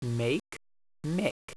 • the short a and e blend (pronounced "eh" like said) (Mpg)